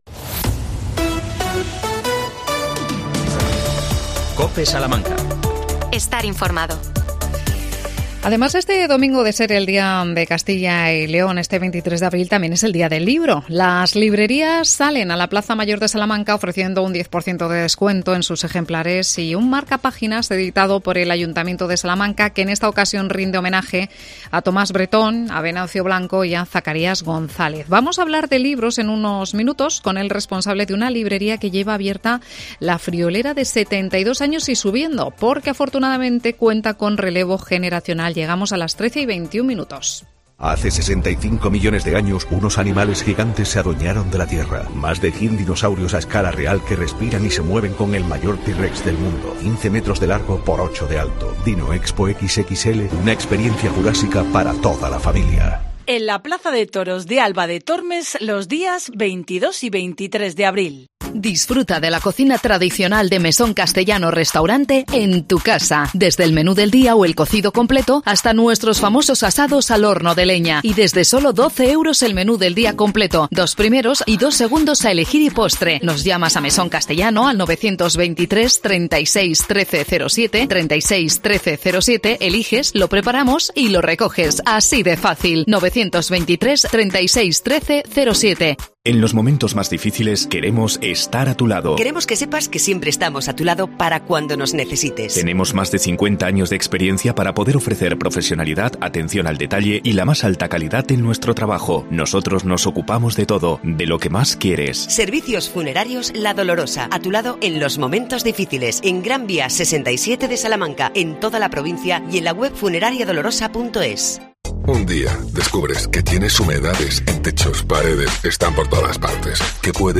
Entrevistamos al librero